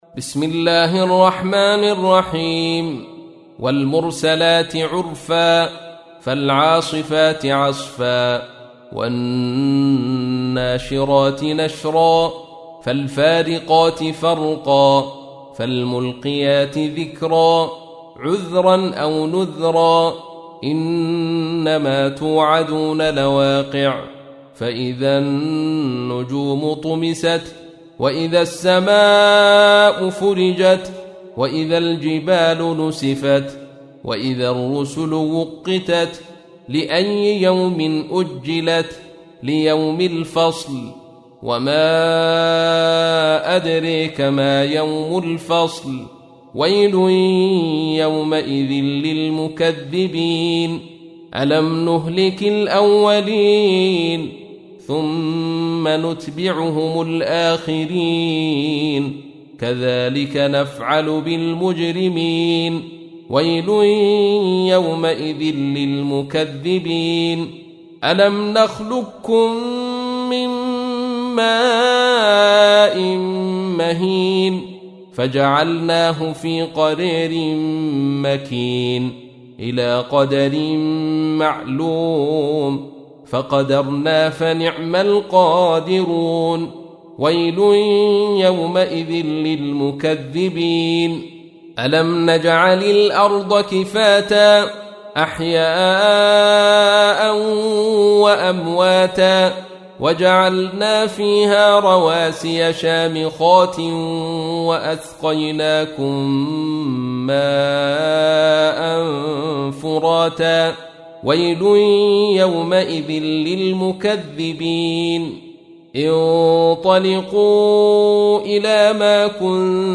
تحميل : 77. سورة المرسلات / القارئ عبد الرشيد صوفي / القرآن الكريم / موقع يا حسين